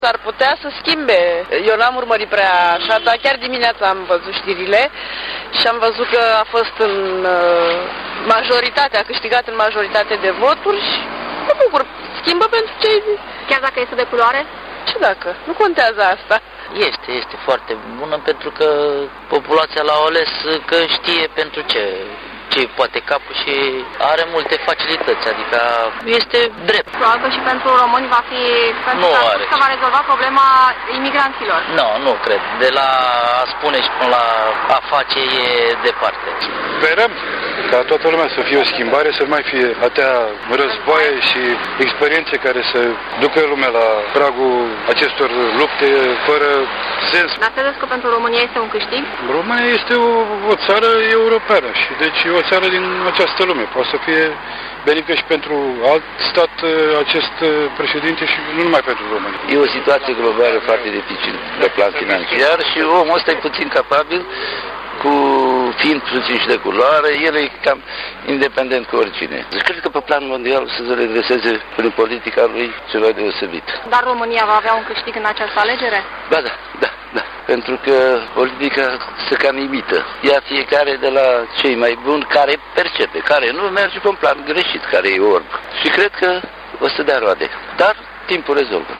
O schimbare bine venită de la care aşteaptă rezolvarea multor probleme mondiale iar dintre cei intervievaţi, tot bărbaţii par să se priceapă mai bine …la politică: